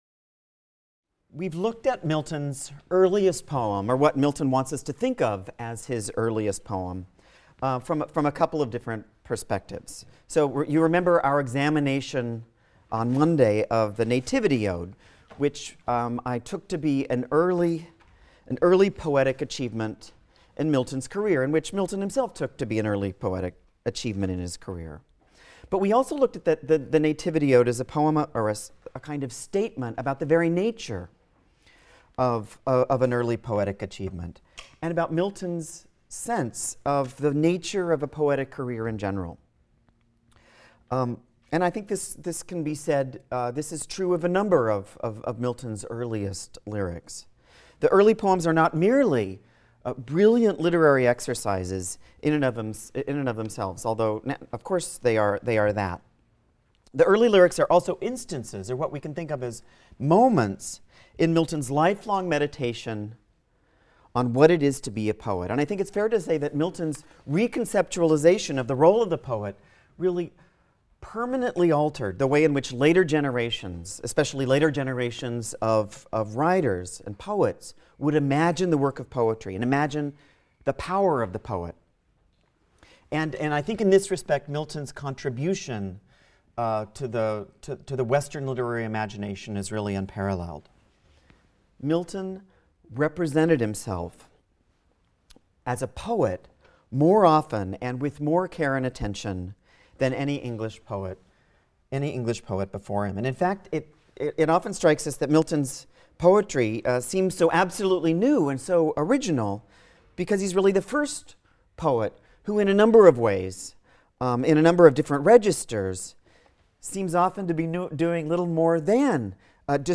ENGL 220 - Lecture 3 - Credible Employment | Open Yale Courses